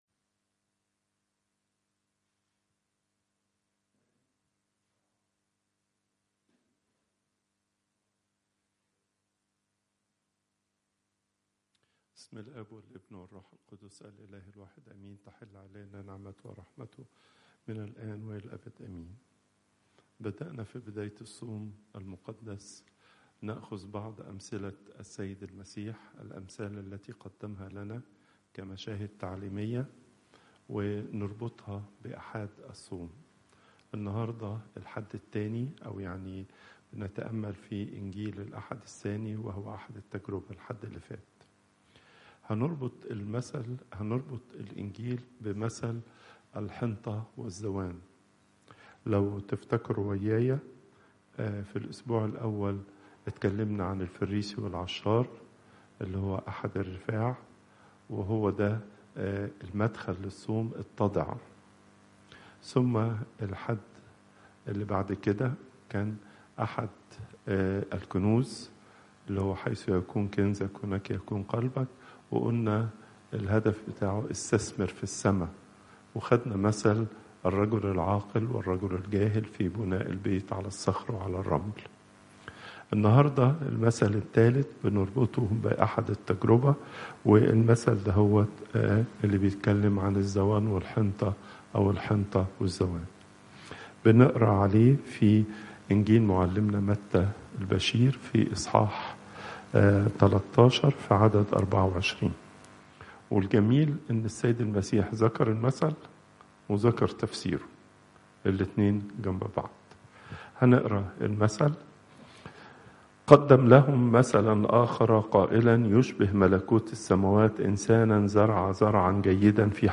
Popup Player Download Audio Pope Twadros II Wednesday, 12 March 2025 37:53 Pope Tawdroes II Weekly Lecture Hits: 278